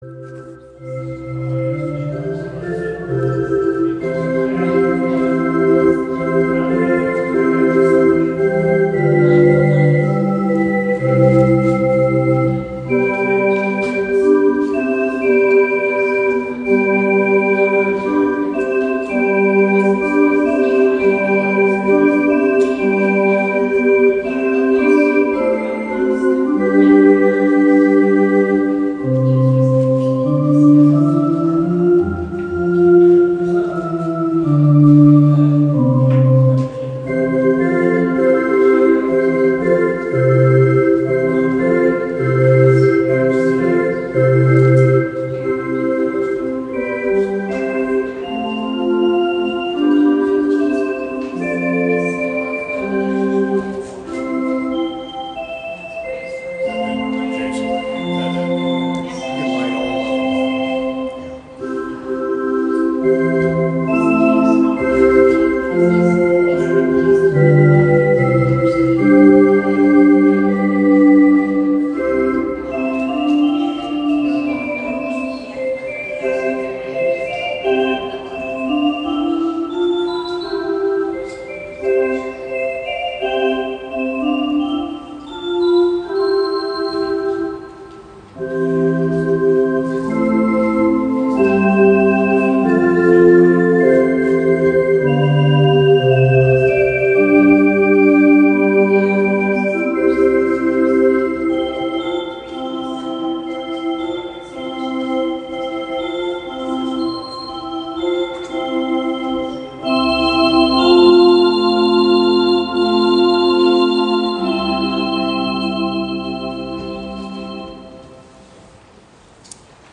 Mar-31-Sonrise-Service.mp3